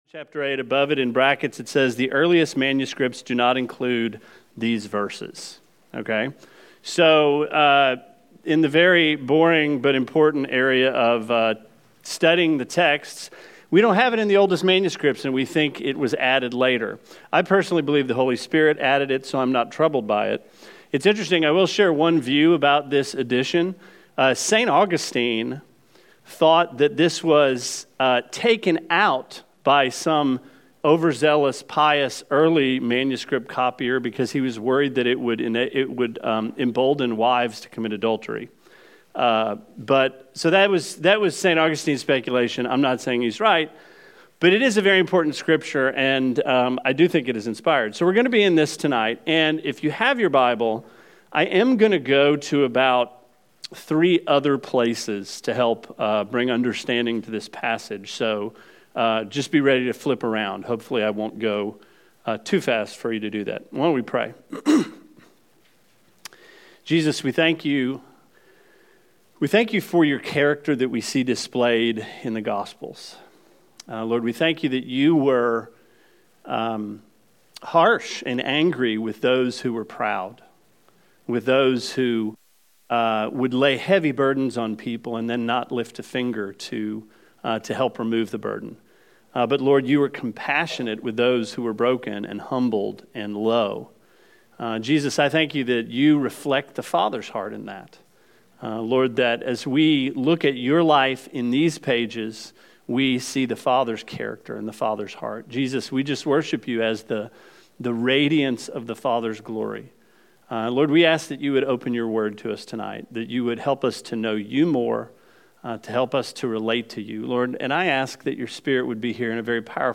Sermon 10/19: John 8